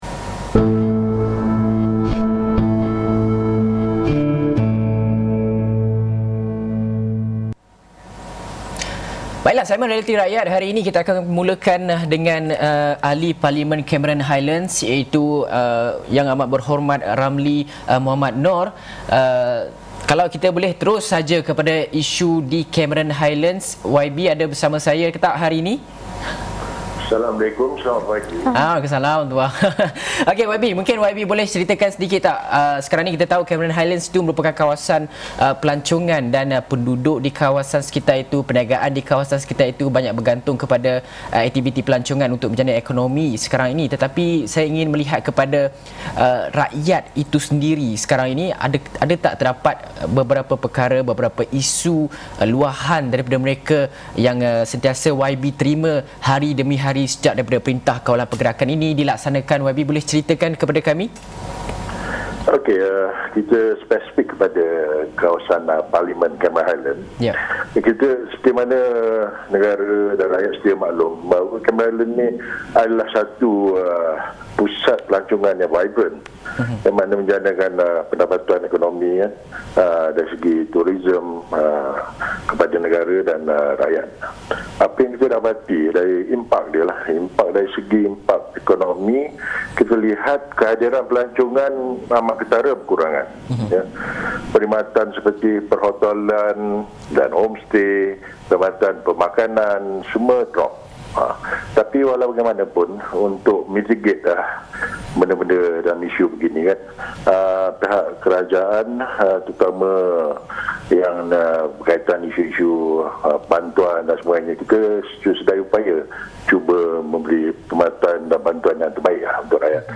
Episod ini telah disiarkan secara langsung dalam program AWANI Pagi, di saluran 501 Astro AWANI, jam 8:30 pagi.